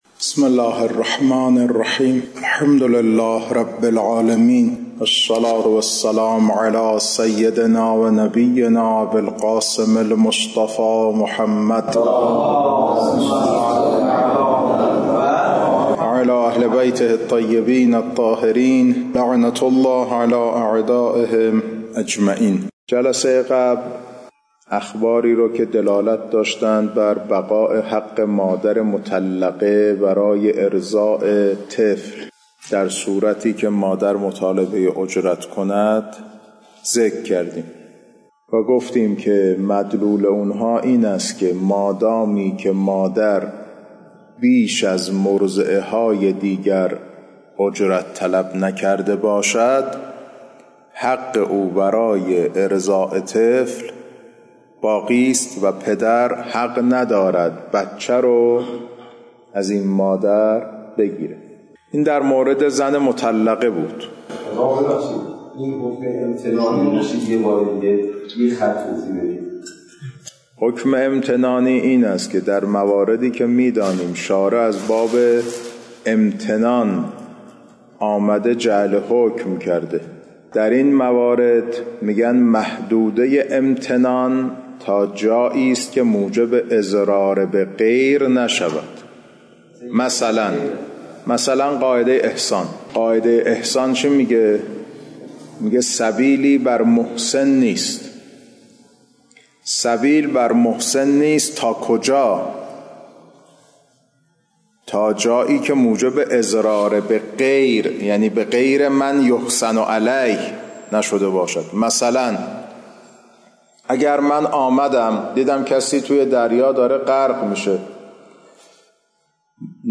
کلاس‌ها خارج فقه